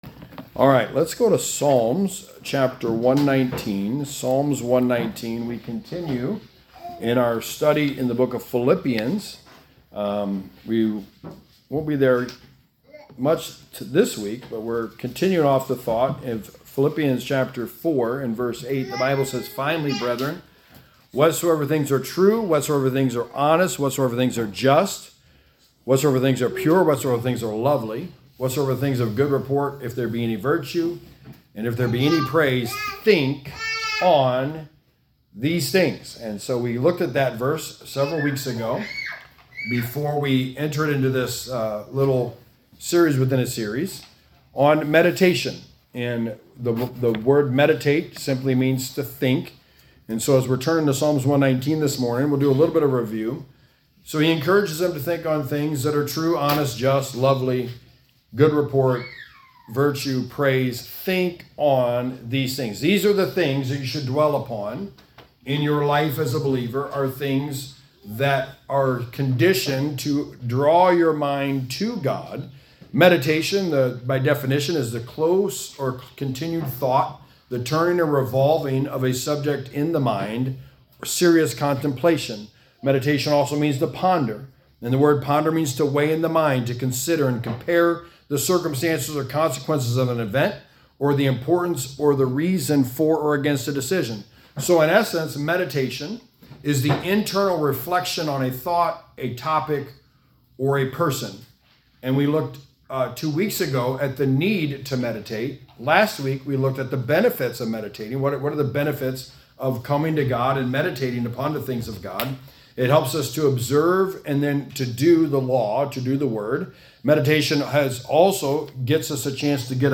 Sermon 29: The Book of Philippians: Meditation 103 – How to Meditate